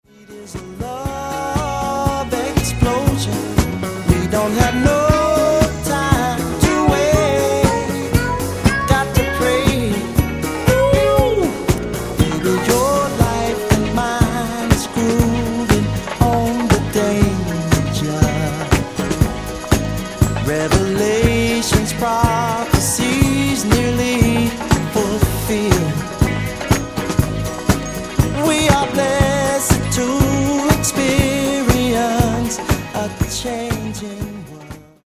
Genere:   Soul | Groove